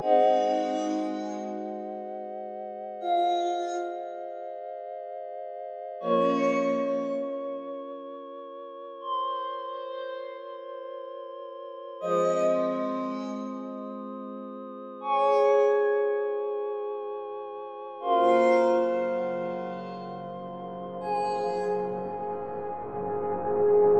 标签： 80 bpm Hip Hop Loops Pad Loops 4.04 MB wav Key : Unknown
声道立体声